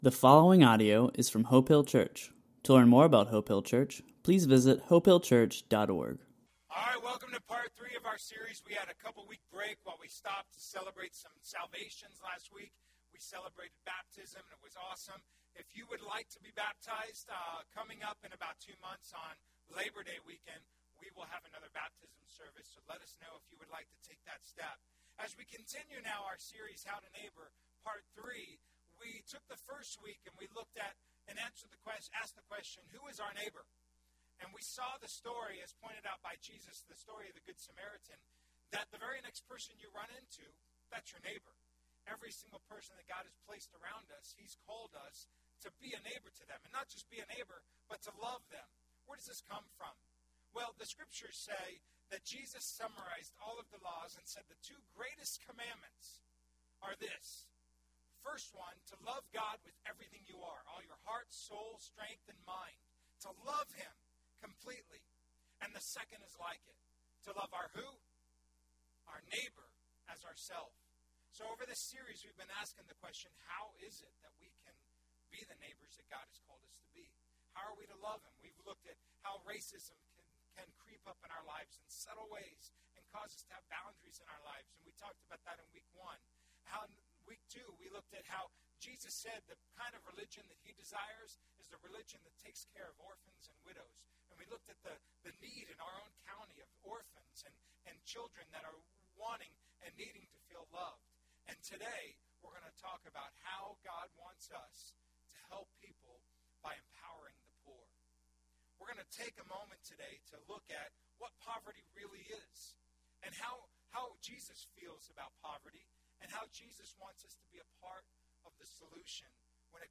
A message from the series "The Bible."